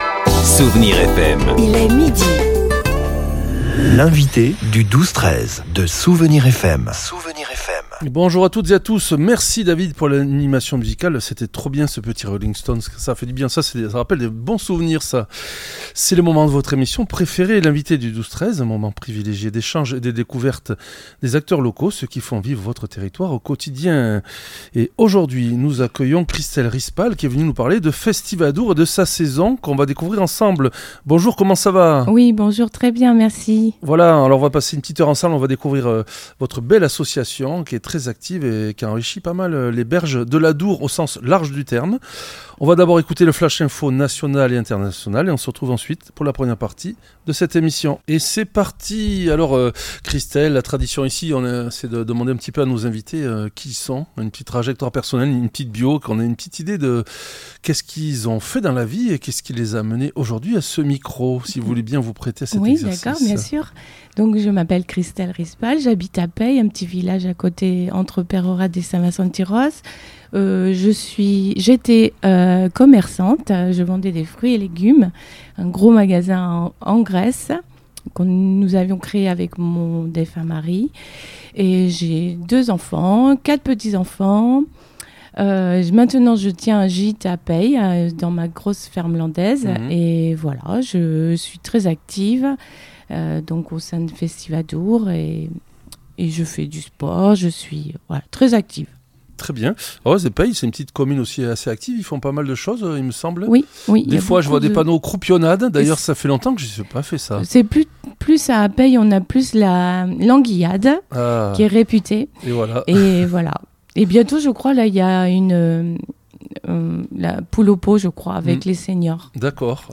L'entretien a mis en lumière des temps forts spectaculaires pour l'été et l'automne, notamment la venue de Stéphane Filoque (vu dans Kaamelott) à Saint-Jean-de-Marsacq le 5 juillet, ou encore le cirque aérien contemplatif du Collectif Maison Courbe en septembre.